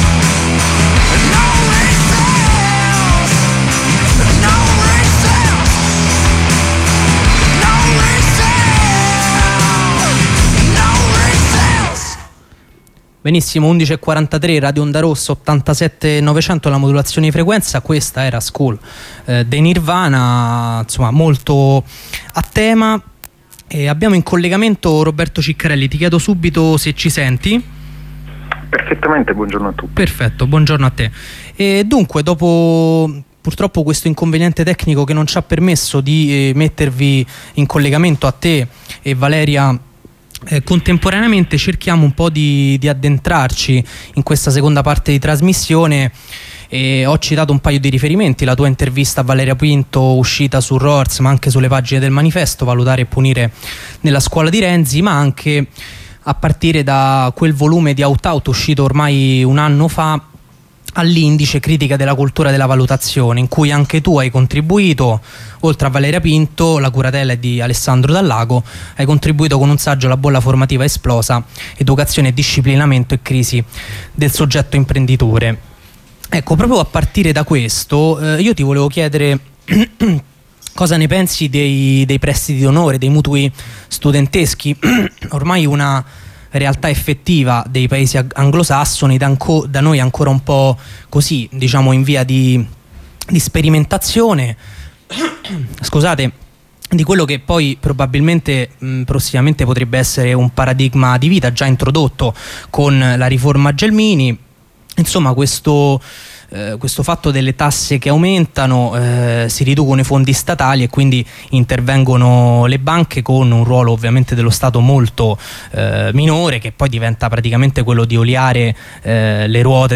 Approfondimento redazionale sul tema dell’università e della ricerca tra precarizzazione e biopolitica.